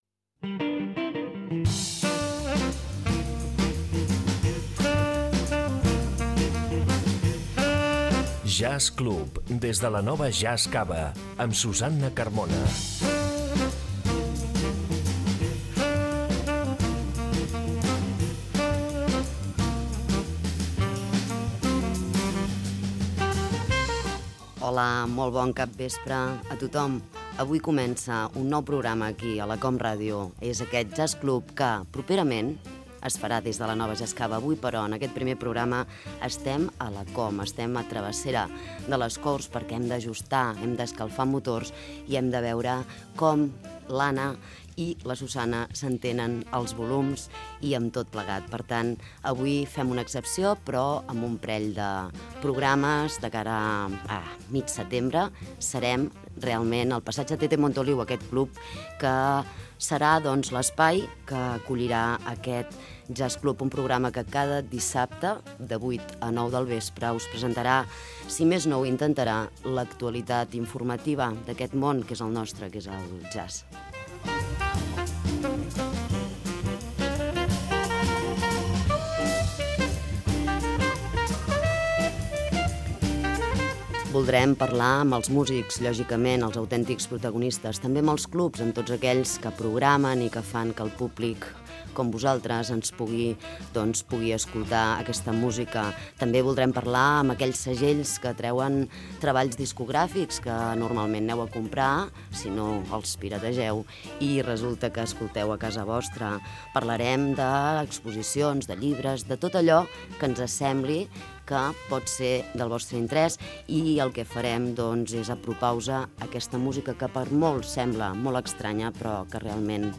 Careta i inici del programa, anunci que properament s'emetrà des de la Nova Jazz Cava de Terrassa.
Musical
FM